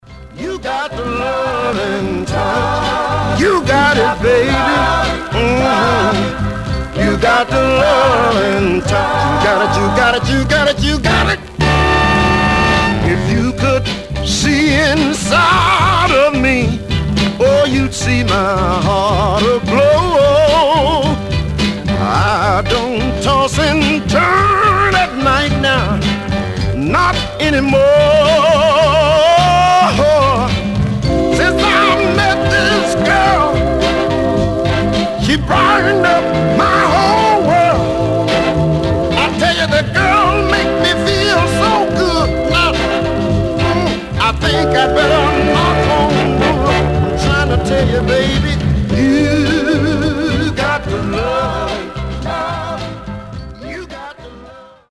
Surface noise/wear
Mono
Soul